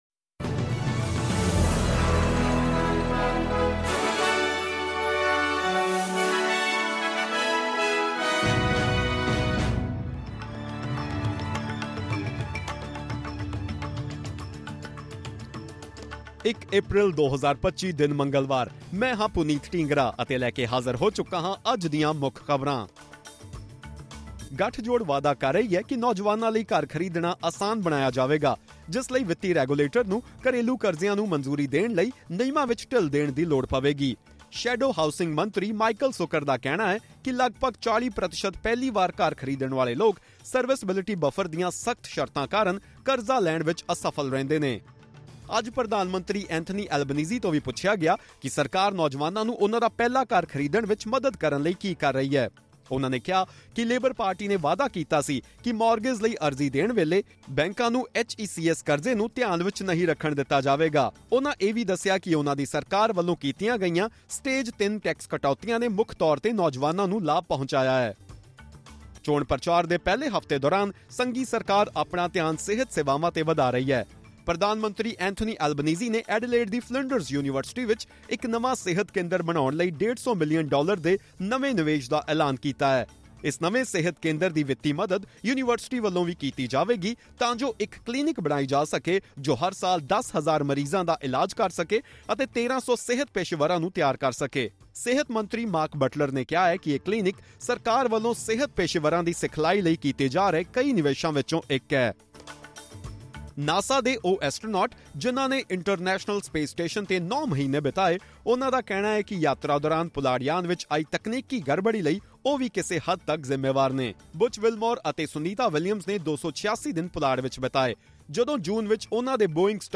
ਖਬਰਨਾਮਾ: ਗੱਠਜੋੜ ਵਾਅਦਾ - ਨੌਜਵਾਨਾਂ ਲਈ ਘਰ ਖਰੀਦਣਾ ਹੋਵੇਗਾ ਅਸਾਨ